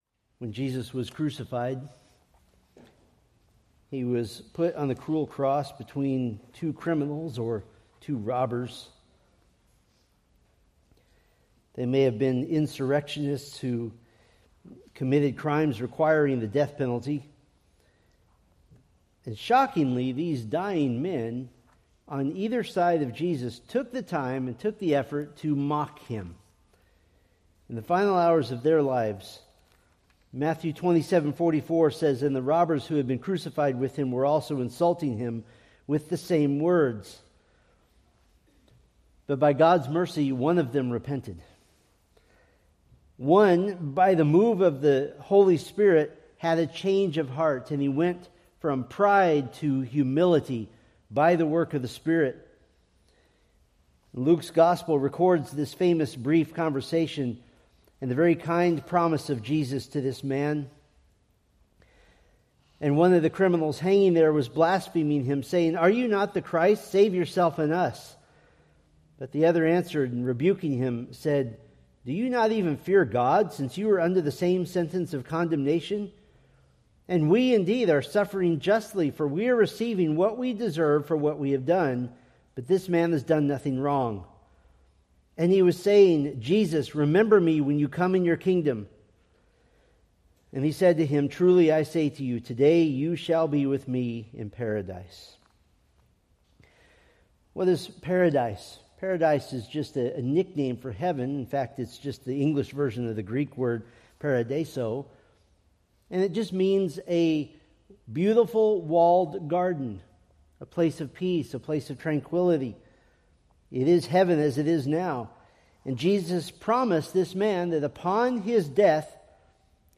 Preached April 3, 2026 from Luke 23:39-43